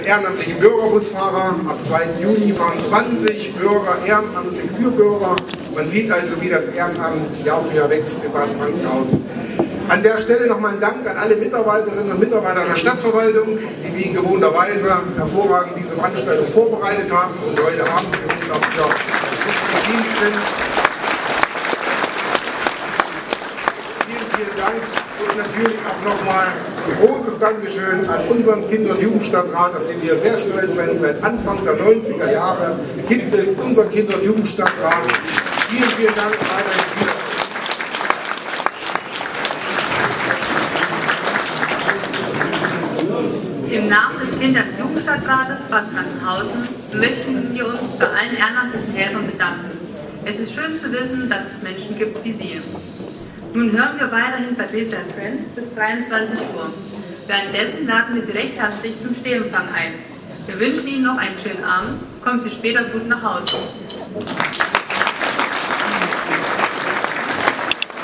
Bereits zum 14. Mal fand gestern am Abend in Bad Frankenhausen die Ehrenamts-Gala der Stadt Bad Frankenhausen statt.
Abschlusrede Bürgermeister